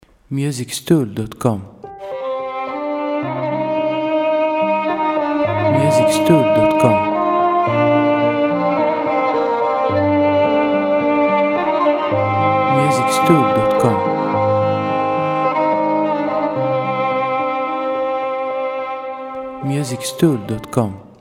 • Type : Instrumental
• Bpm : Largo / Lento
• Genre : Ambient / Cinematic / Oriental